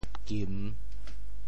“噤”字用潮州话怎么说？
噤 部首拼音 部首 口 总笔划 16 部外笔划 13 普通话 jìn 潮州发音 潮州 gim3 文 中文解释 噤 <动> 闭口 [close one's mouth] 口禁,口闭也。